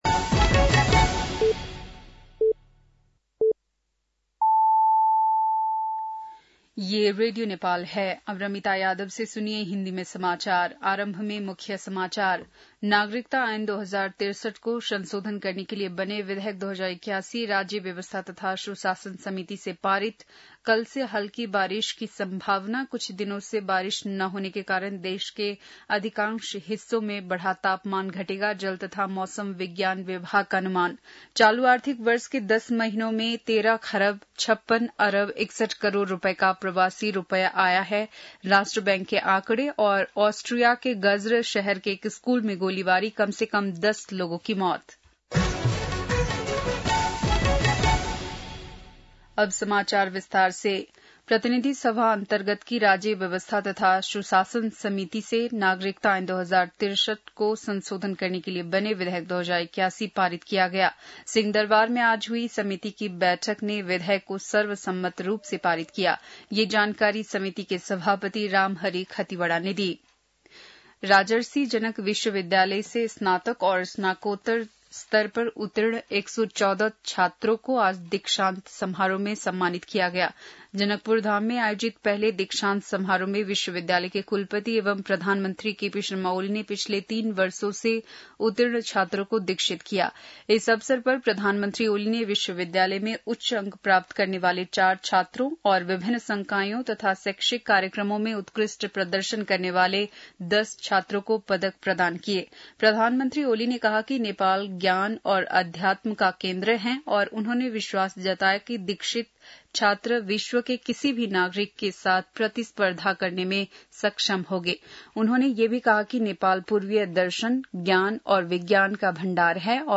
बेलुकी १० बजेको हिन्दी समाचार : २७ जेठ , २०८२
10-pm-hindi-news-2-27.mp3